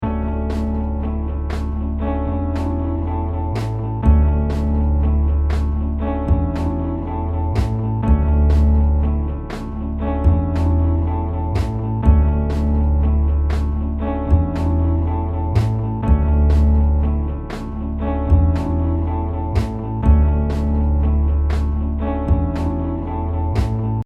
This has an upright bass track (synth). Played in with a normal text keyboard. It mirrors the underlying loop’s bass riff.
But it’s a little more full sounding.